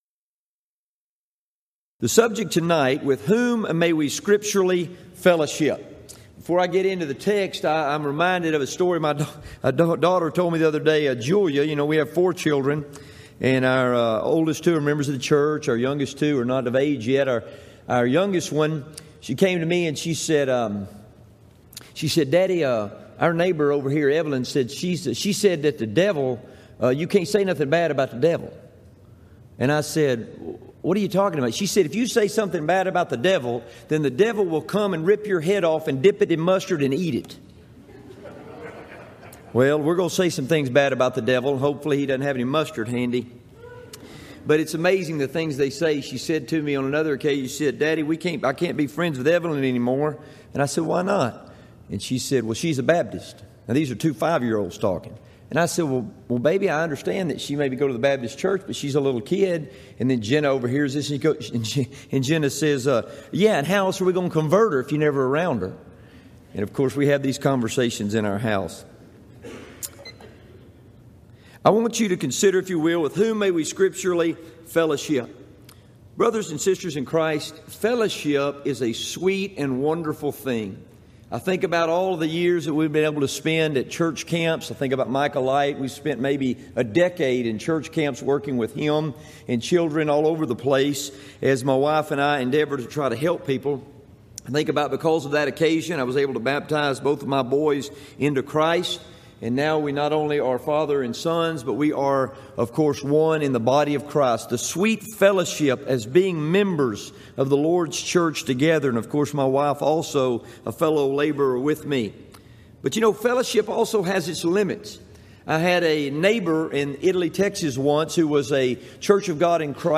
Event: 24th Annual Gulf Coast Lectures
lecture